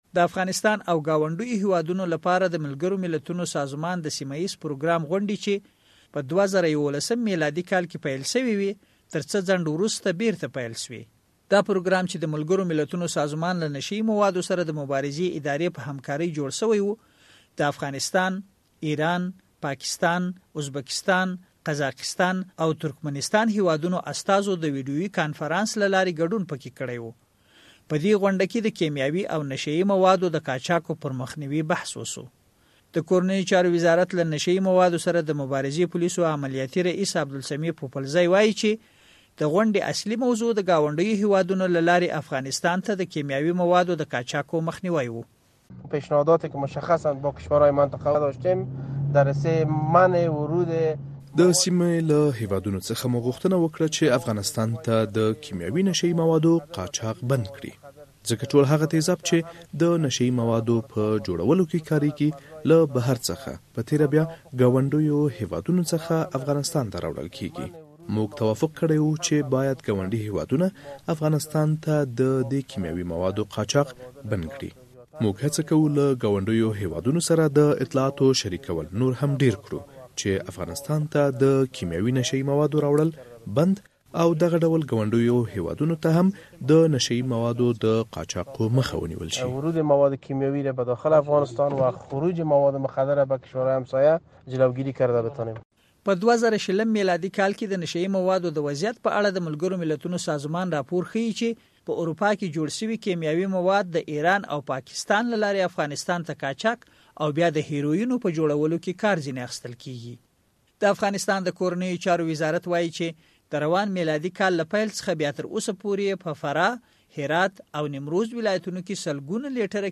ګاونډيو هېوادونه ته د نشه يي موادو د قاچاق مخنیوي راپور